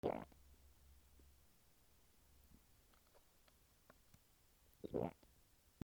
のど 飲み込む
『ゴク』